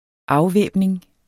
afvæbning substantiv, fælleskøn Bøjning -en, -er, -erne Udtale [ ˈɑwˌvεˀbneŋ ] Betydninger 1. fratagelse af alle våben Efter krigens afslutning var han med i afvæbningen af tyske tropper JyPAarh2013 JP Aarhus (avis), 2013.